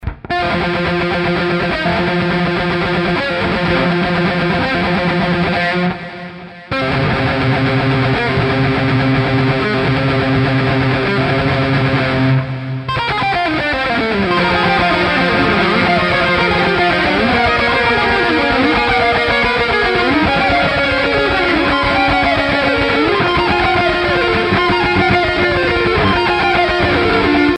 Вниз  Играем на гитаре
Вообще это черновик, первый день учу :-D Но слушаю и ржать охото :gy:
Тебе чуток быстрее надо :)